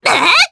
Viska-Vox_Attack1_jp.wav